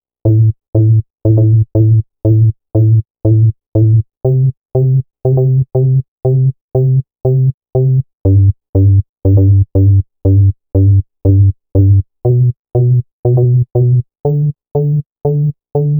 TSNRG2 Off Bass 007.wav